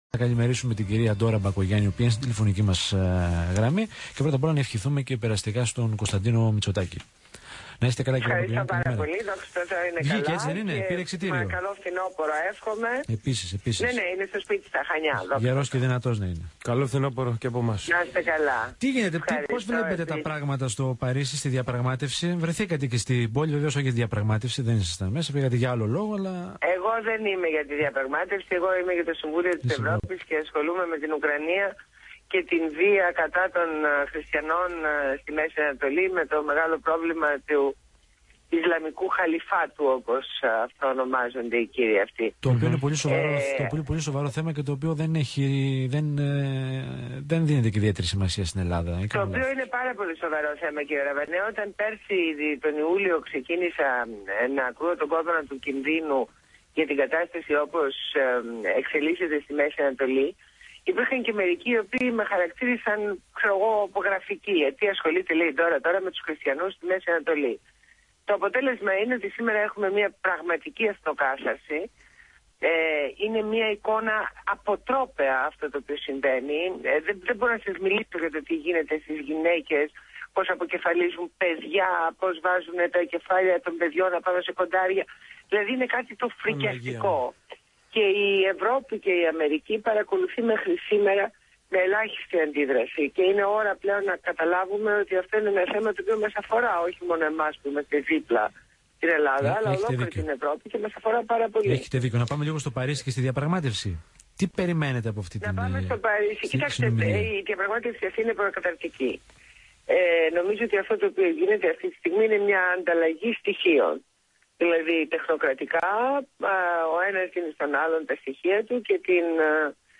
Ακούστε τη ραδιοφωνική συνέντευξη